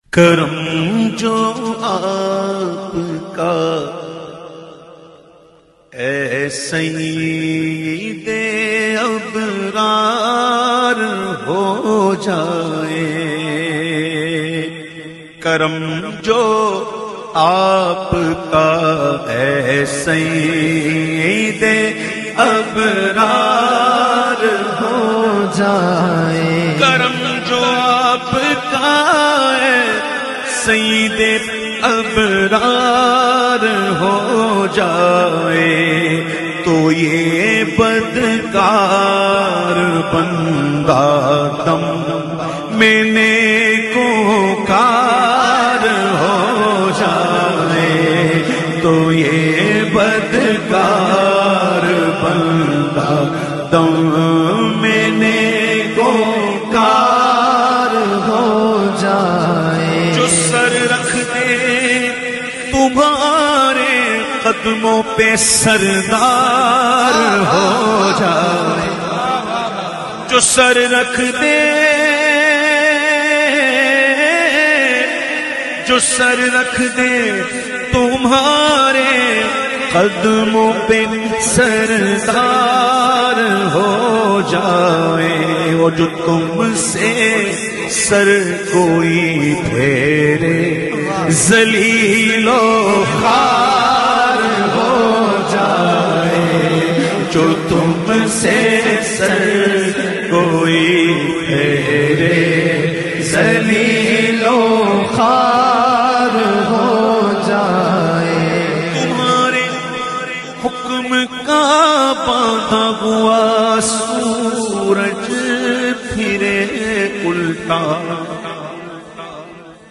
The Naat Sharif Karam Jo Aapka recited by famous Naat Khawan of Pakistan owaise qadri.